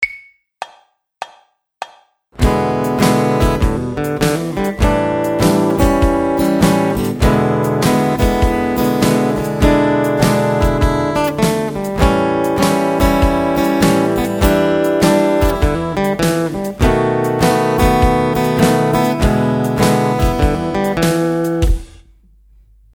Here is a blues based song in the key of E major that combines chord variations with the E minor pentatonic scale.